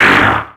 Cri de Medhyèna dans Pokémon X et Y.